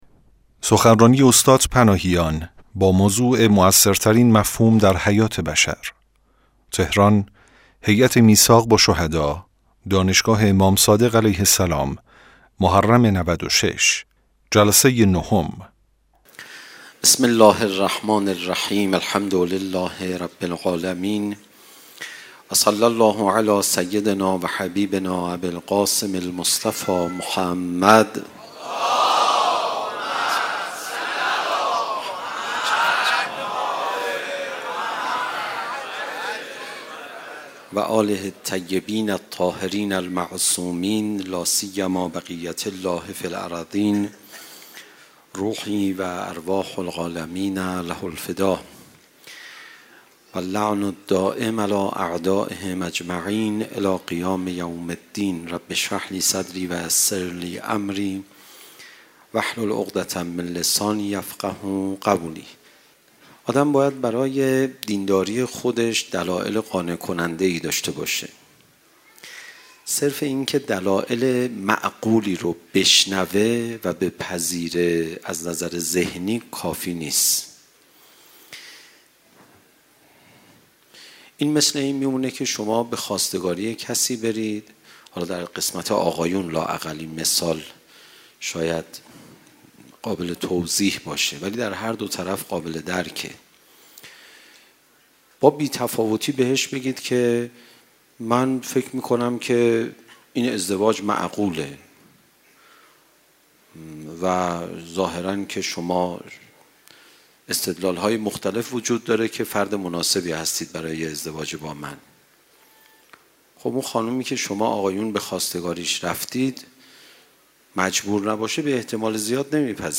شب نهم محرم 96 - دانشگاه امام صادق علیه السلام